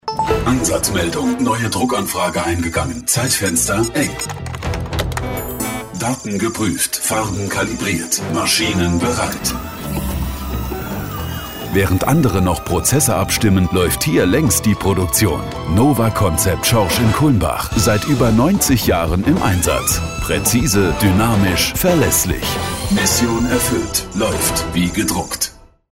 Ein besonderer Bestandteil der Kooperation ist unser eigener Radiospot, der regelmäßig ausgestrahlt wird und unsere Botschaft direkt zu den Menschen bringt.